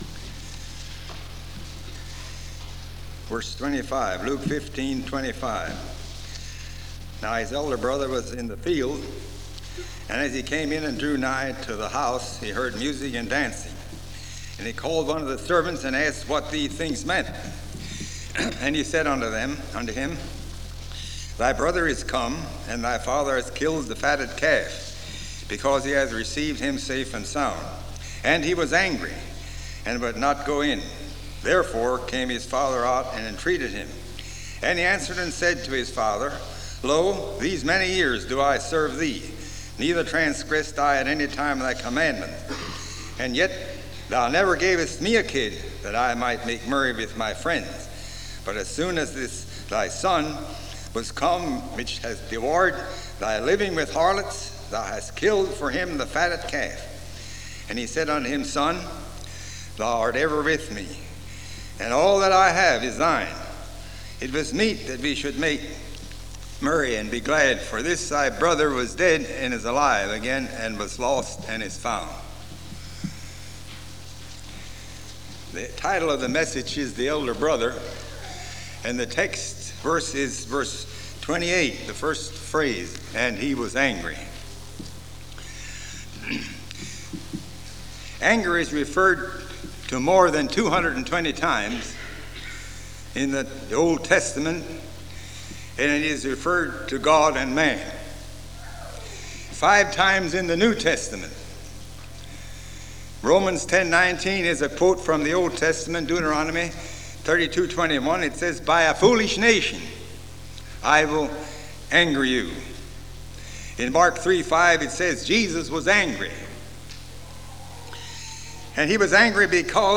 This sermon was preached at Shade Mount.